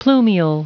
Prononciation du mot plumule en anglais (fichier audio)
Prononciation du mot : plumule